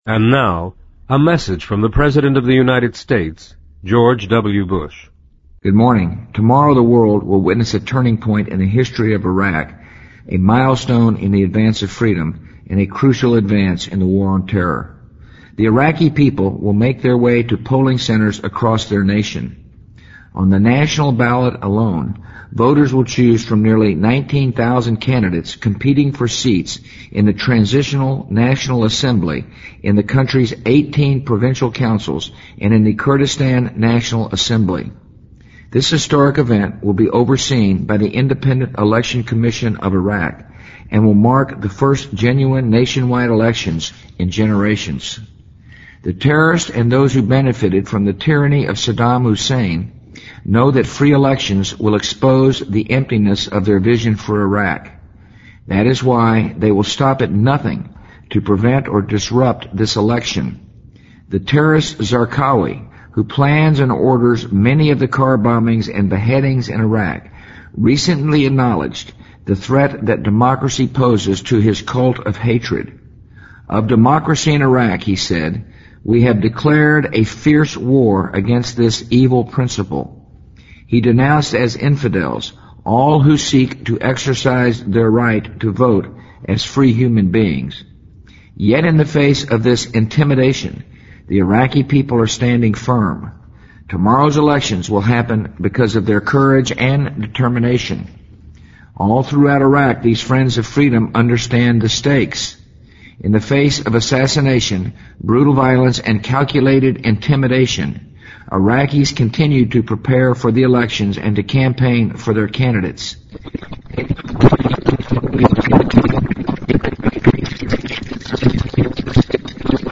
President Bush-2005-01-29电台演说 听力文件下载—在线英语听力室
在线英语听力室President Bush-2005-01-29电台演说的听力文件下载,美国总统电台演说-在线英语听力室